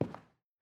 Sounds / sfx / Footsteps / Carpet / Carpet-05.wav
Carpet-05.wav